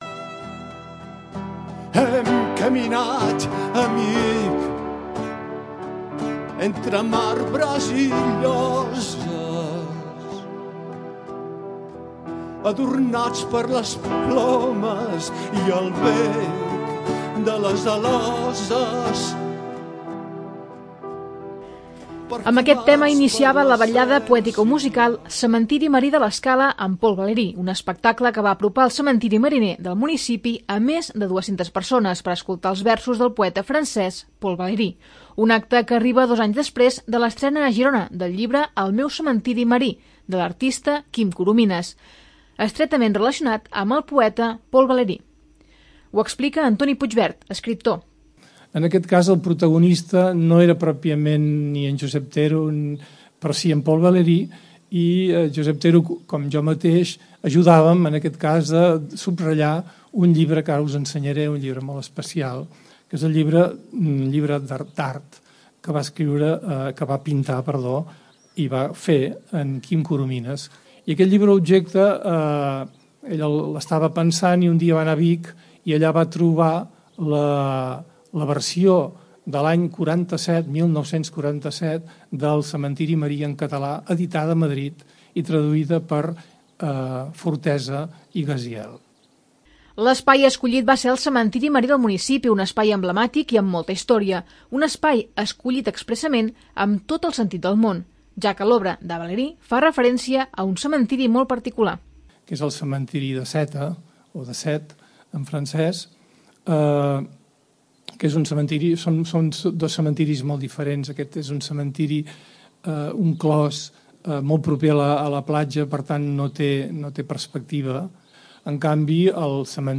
Amb aquest tema iniciava la vetllada poetico-musical 'Cementiri Marí de l'Escala amb Paul Valéry', un espectacle que va apropar al cementiri mariner del municipi a més de 200 persones per escoltar els versos del poeta francès Paul Valéry.